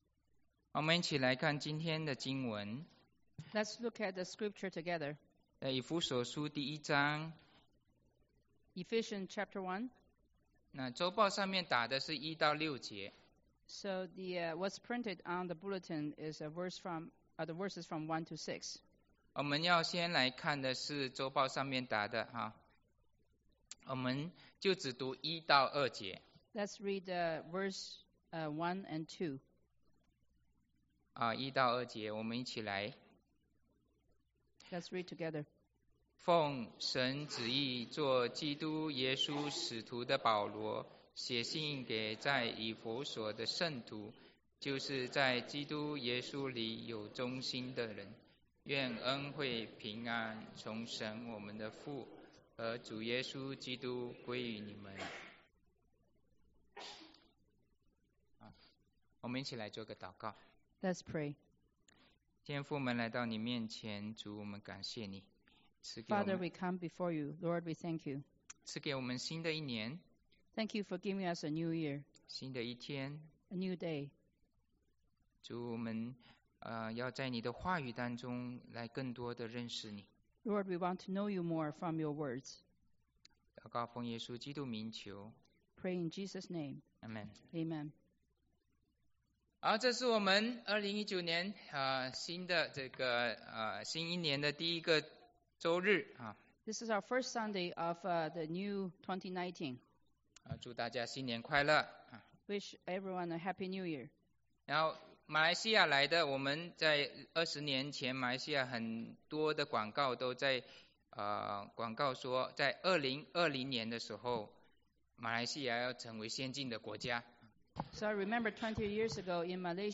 Mandarin Sermons – Page 34 – 安城華人基督教會